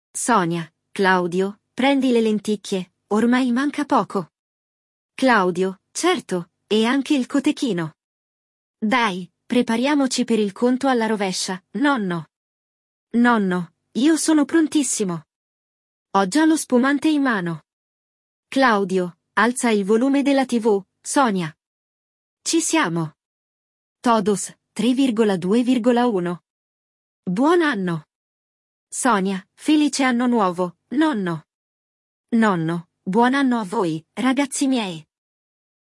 Neste episódio, vamos ouvir um diálogo entre Sonia, Claudio e o vovô, alguns membros de uma família que celebram juntos a chegada do ano novo! Descubra como festejar a passagem de ano na Itália e o que é o “conto alla rovescia”!
Il dialogo